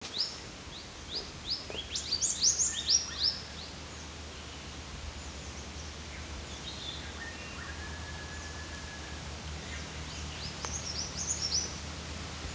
5. 2. 울음소리
주황할미새사촌의 울음소리
주황할미새사촌의 울음소리는 여러 개의 "윕-스윕" 소리가 이어지는 휘파람과 같다.